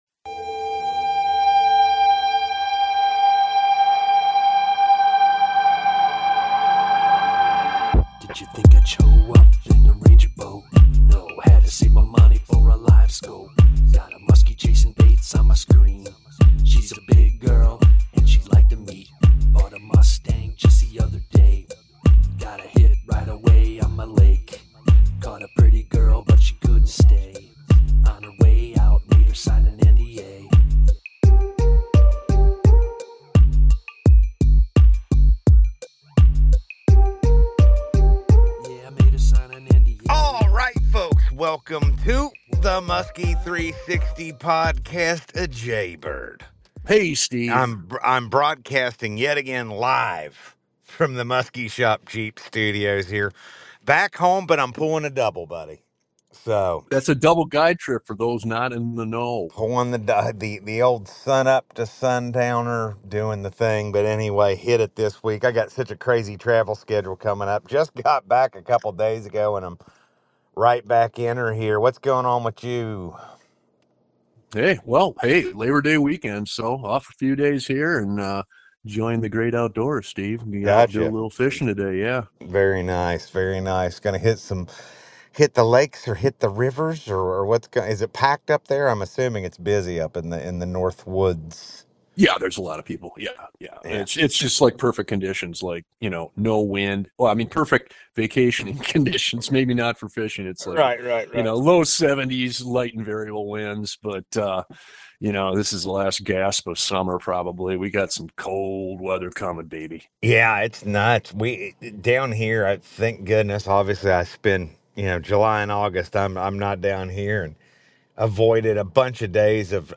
conversations with the best musky fishermen in the world. Musky 360 presents the best musky fishing info.